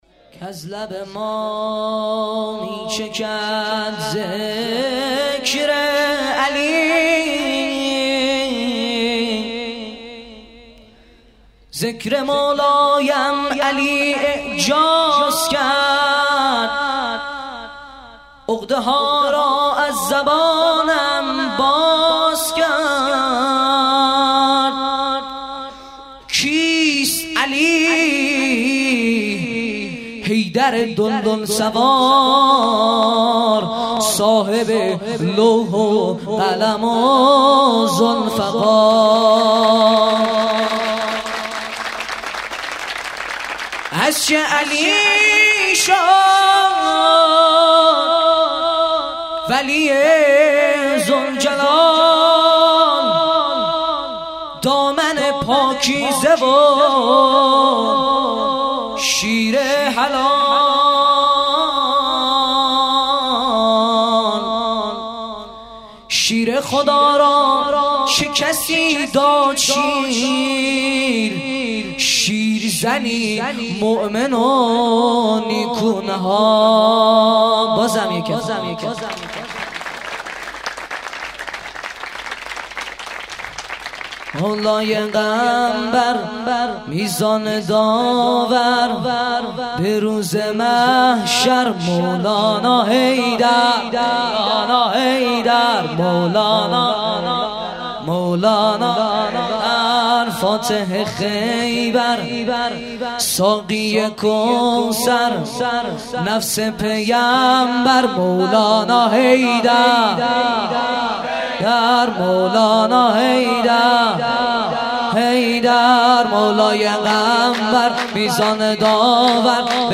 خیمه گاه - هیئت بچه های فاطمه (س) - سرود | کز لب ما میچکد ذکر علی